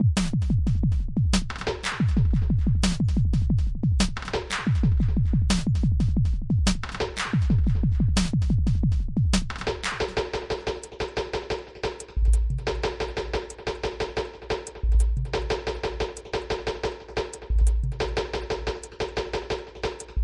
描述：带有分层打击乐的空间旋律。
Tag: 矛盾 循环 电子 环境 郁郁葱葱 击败